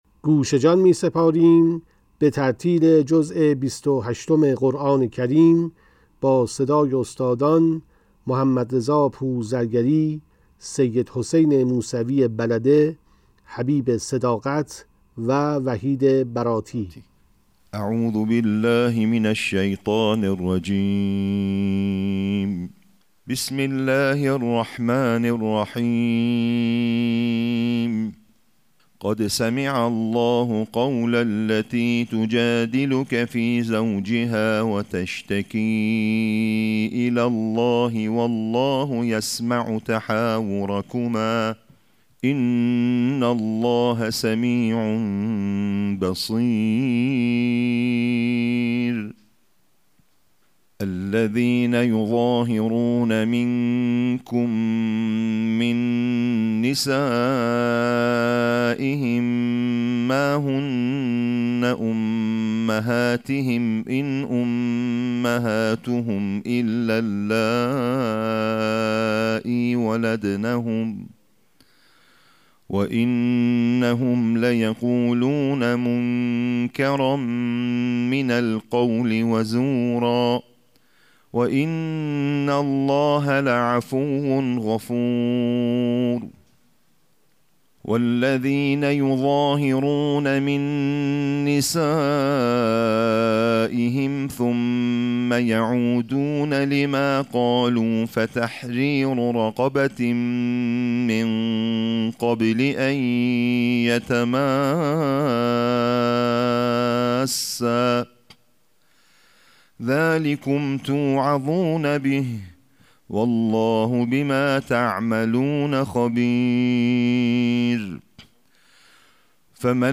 قرائت ترتیل جزء بیست و هشتم قرآن با صدای قاریان بین‌المللی + صوت
نسخه باکیفیت تلاوت جزء بیست و هشتم قرآن با صدای قاریان بین‌المللی